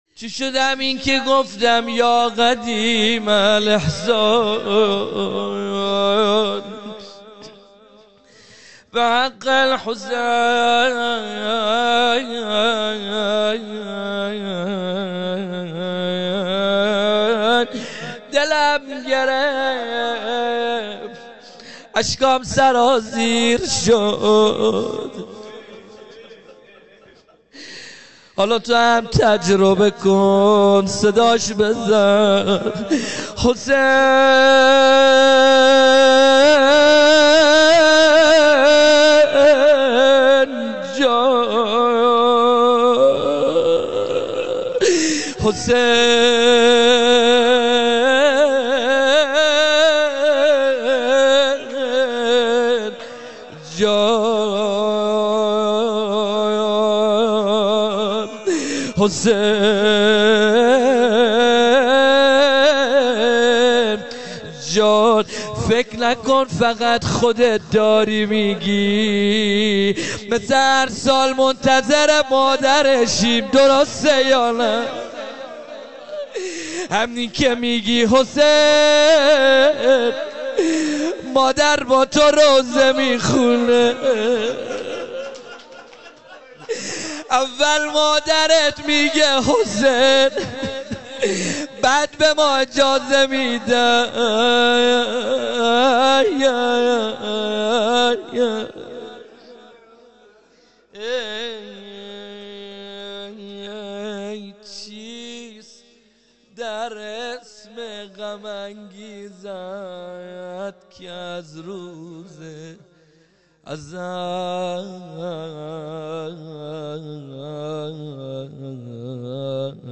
روضه مداحی | چیست در اسم غم انگیزت که از روز ازل
مراسم استقبال از محرم الحرام 1442 ه.ق